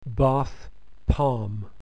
British English
bathpalmRP.mp3